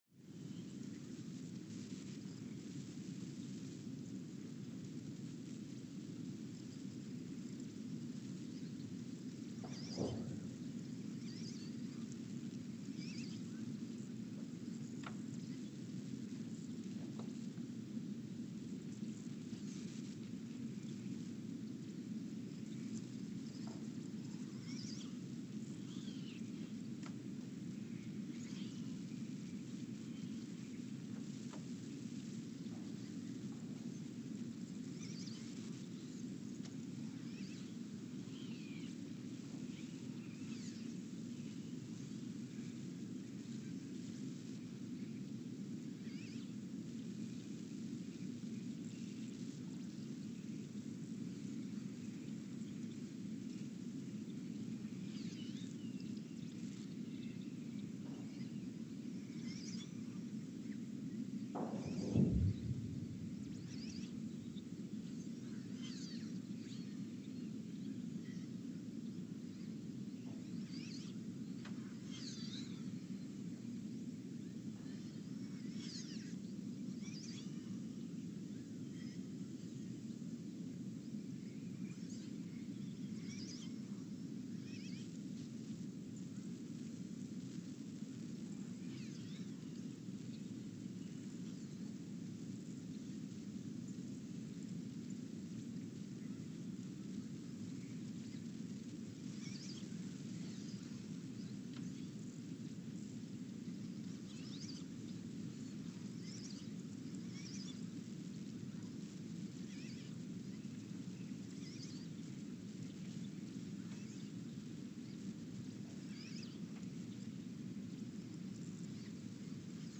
Ulaanbaatar, Mongolia (seismic) archived on February 20, 2024
No events.
Sensor : STS-1V/VBB
Speedup : ×900 (transposed up about 10 octaves)
Loop duration (audio) : 03:12 (stereo)